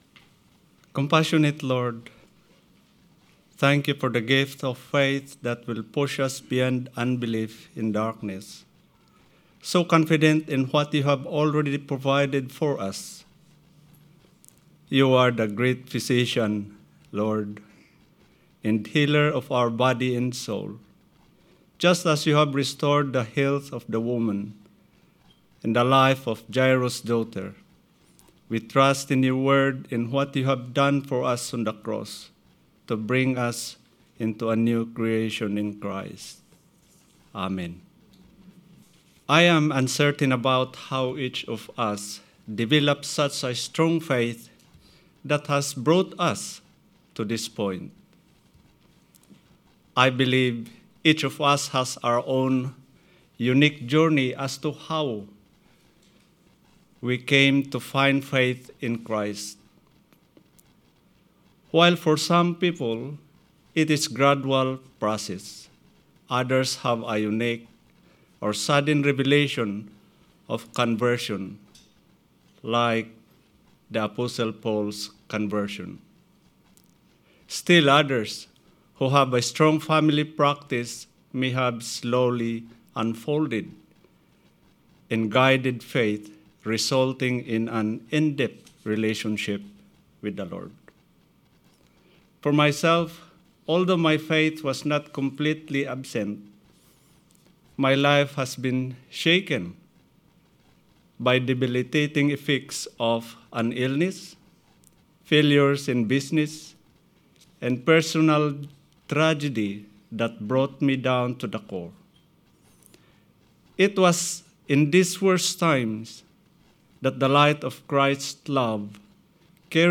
Sermon 30th June – A Lighthouse to the community